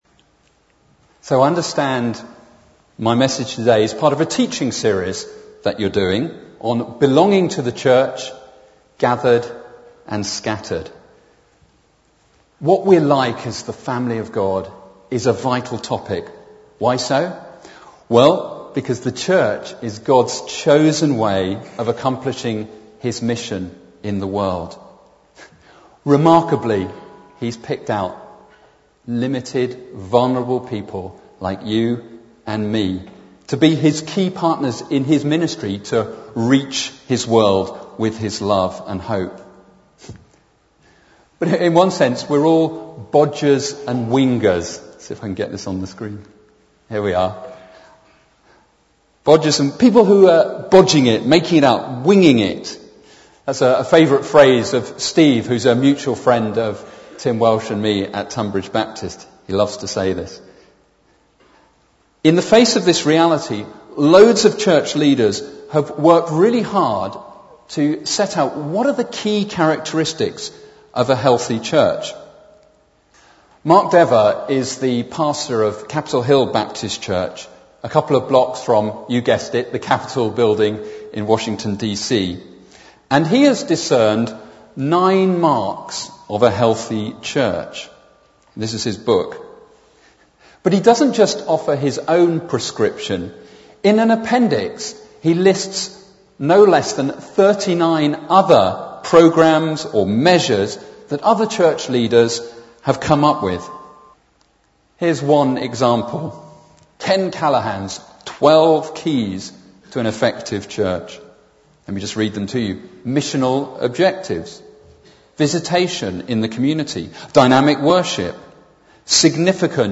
speaking as part of our Belonging to the Church series